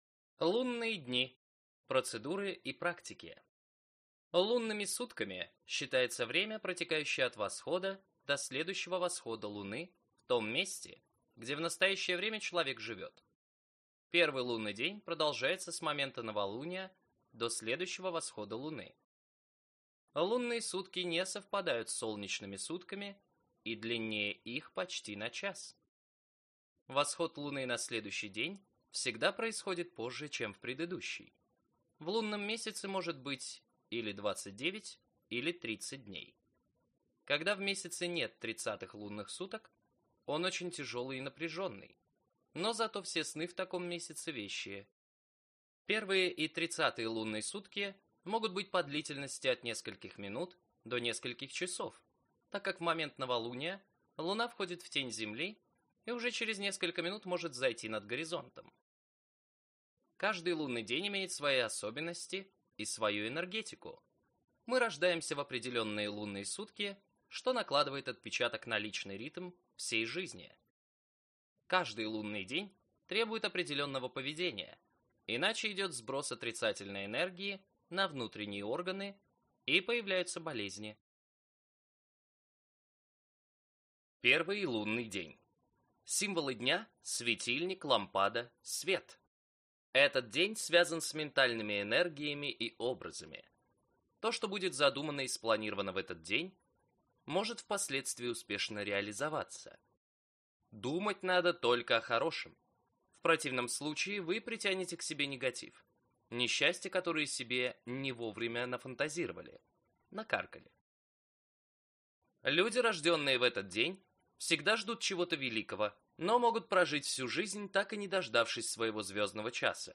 Аудиокнига Правила Луны на каждый день | Библиотека аудиокниг